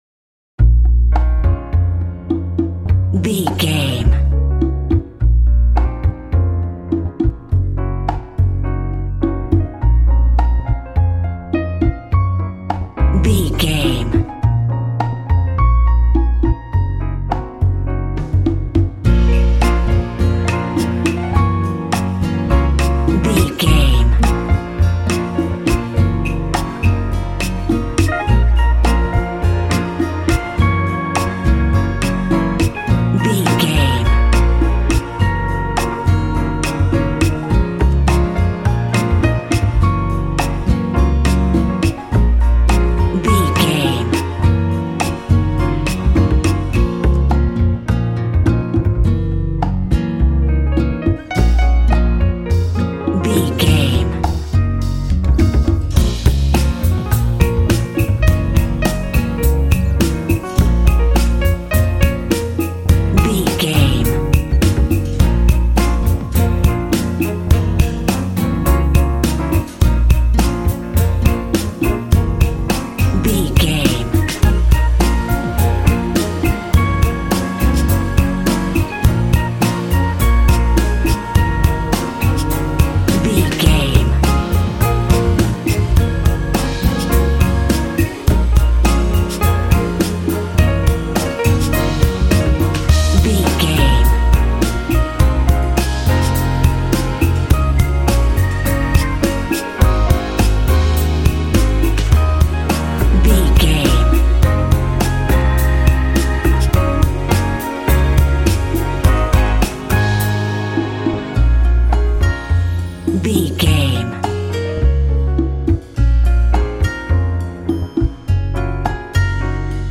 Uplifting
Aeolian/Minor
B♭
groovy
percussion
acoustic guitar
bass guitar
drums
strings
conga
smooth latin jazz